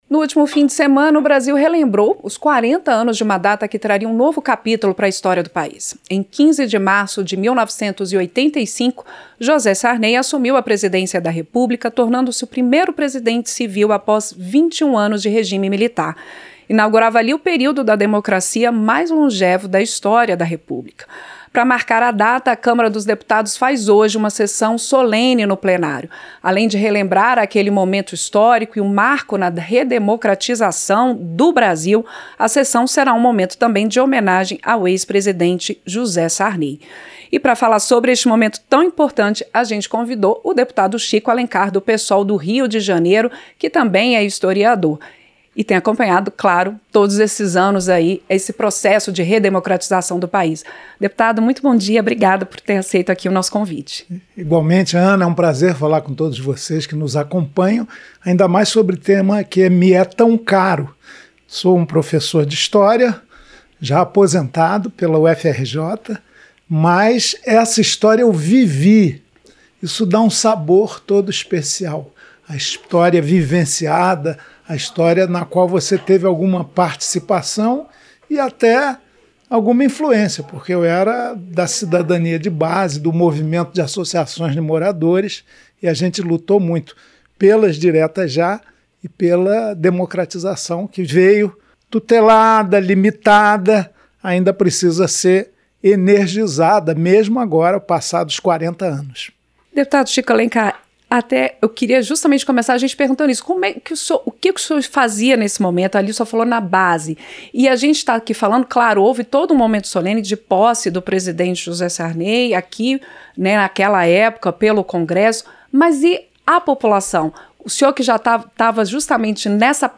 Entrevista - Dep. Chico Alencar (Psol-RJ)